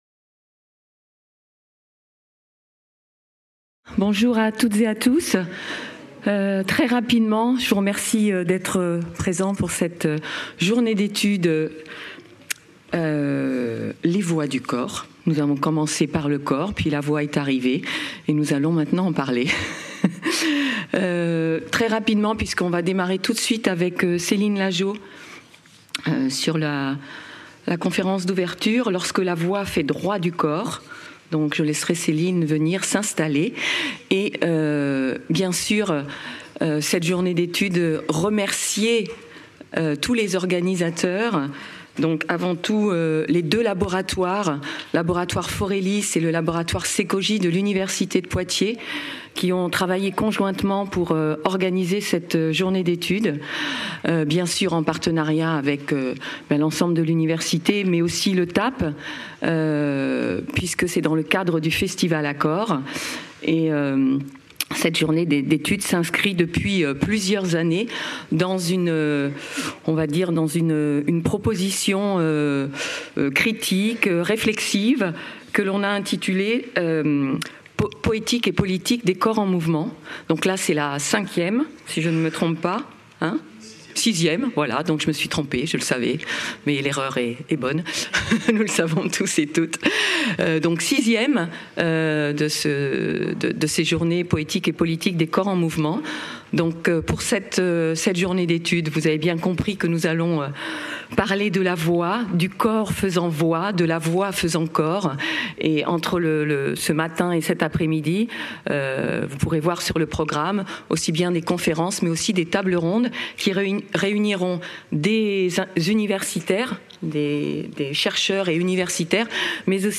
Conférence d’introduction